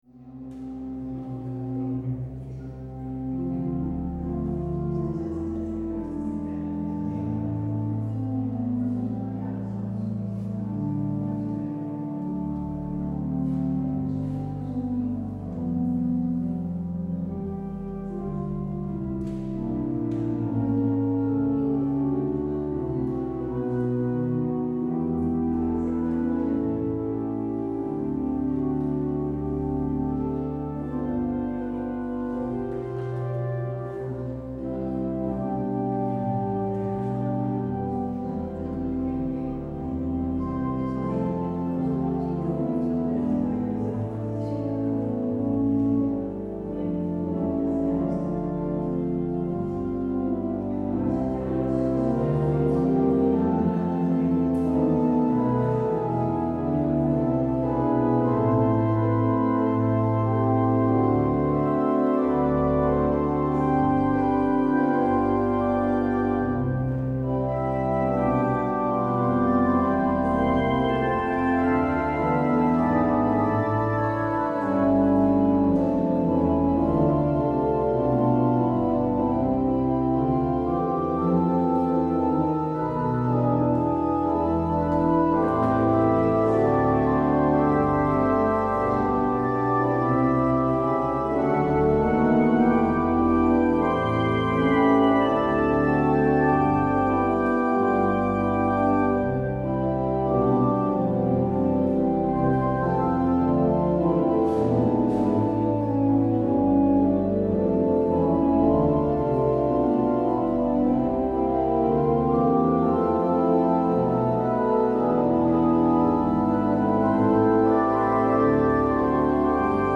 Het openingslied is: NLB 687: 1, 2 en 3.